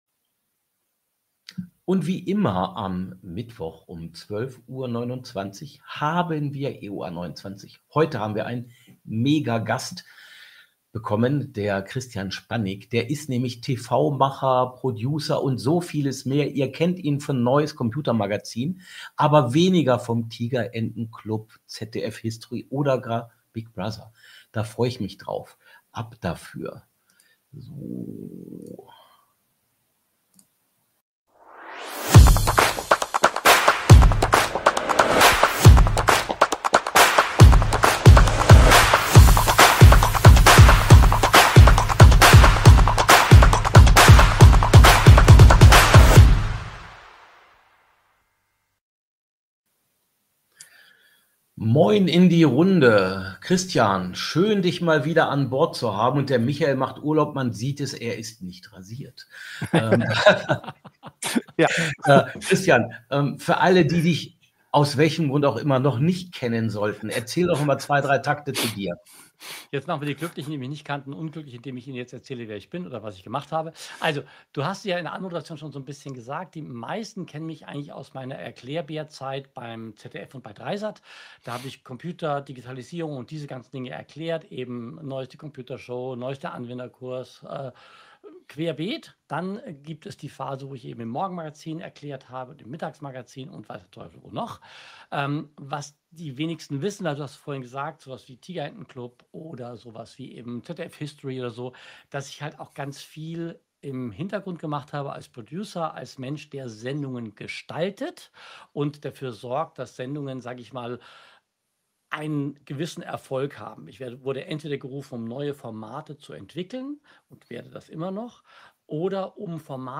Freut Euch auf viel praktische Tipps - wie immer im LiveStream am Mittwoch, pünktlich um 12:29 Uhr.